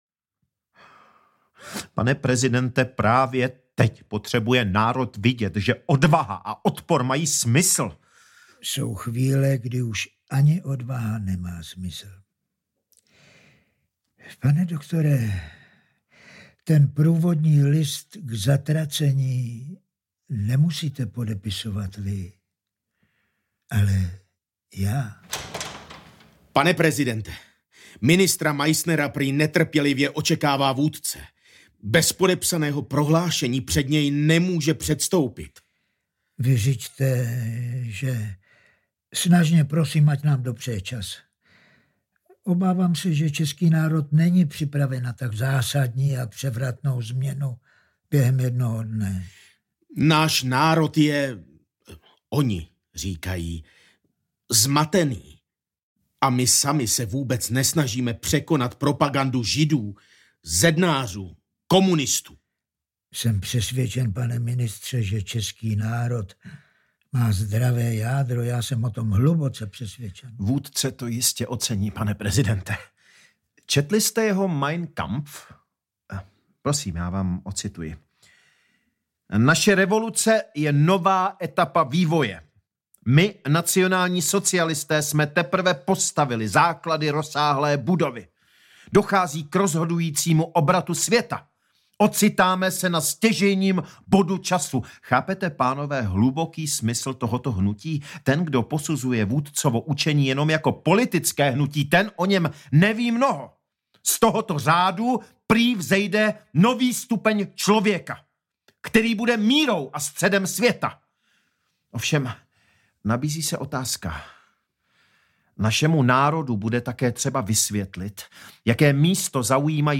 Hácha 1939 audiokniha
Ukázka z knihy